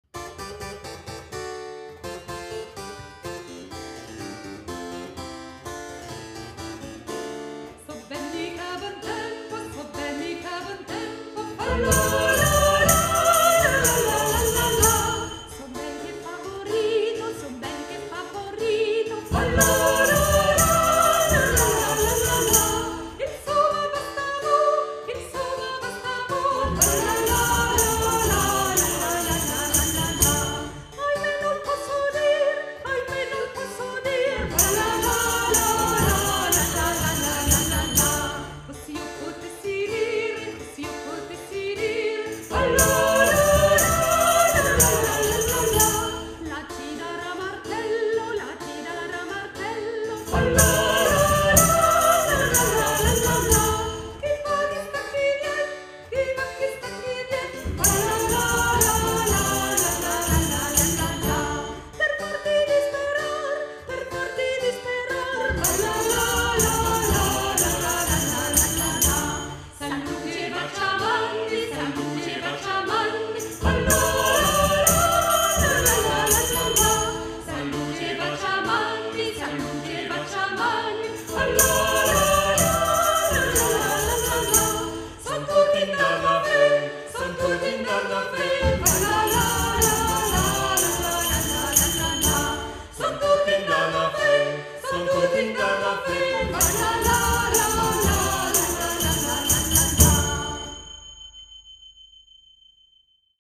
Musique ancienne, Renaissance et baroque
Luth
Flûtes à bec
Percussions
Violes
Violon
Clavecin